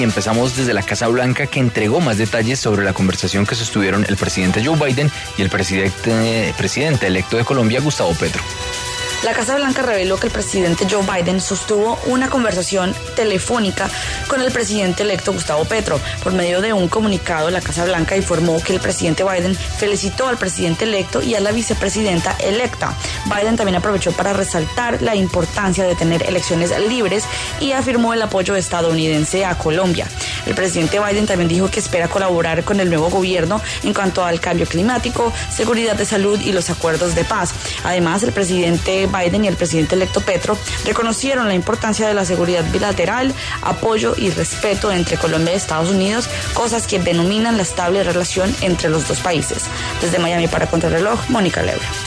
Conversación entre Biden y Petro